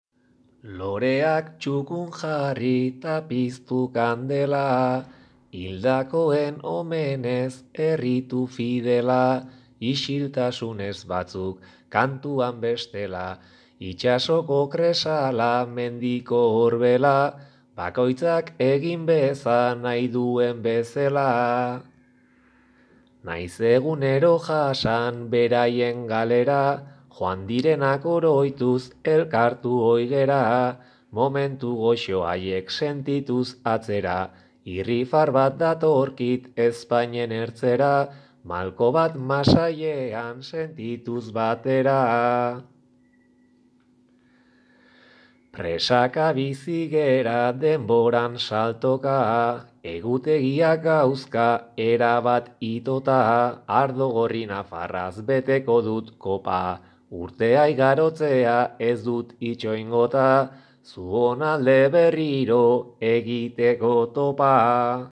zuongatik!' bertso sorta.